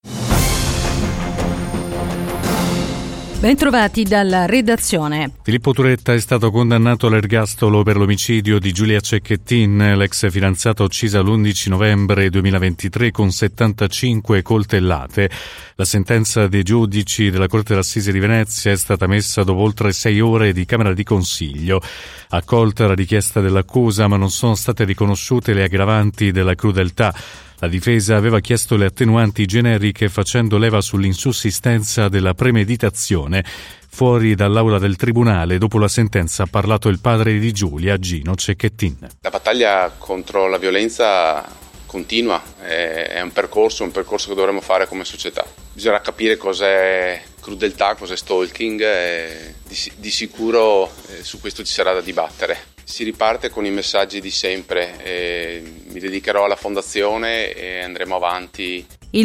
GIORNALE RADIO EDIZIONE DELLE 17